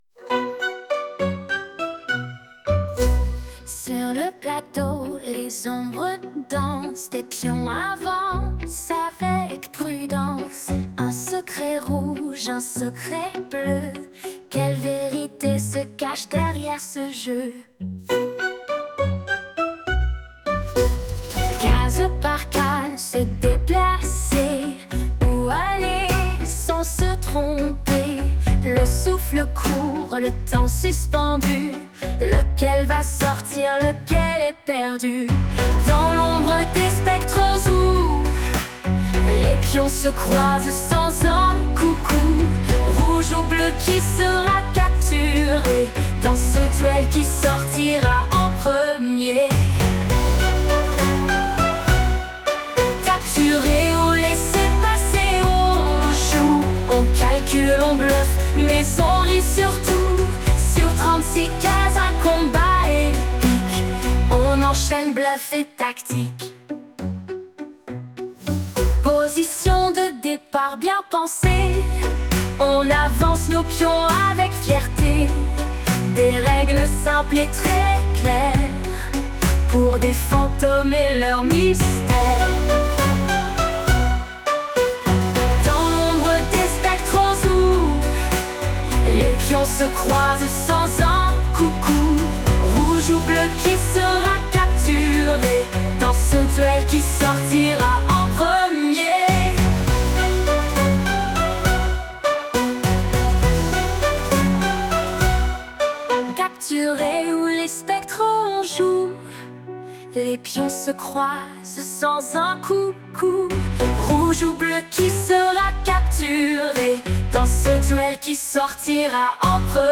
On continue avec du vieux classique, mais est-ce que vous le connaissez ? musique jeu 96